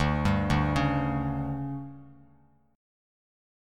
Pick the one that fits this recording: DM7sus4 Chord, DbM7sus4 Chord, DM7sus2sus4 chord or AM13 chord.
DM7sus4 Chord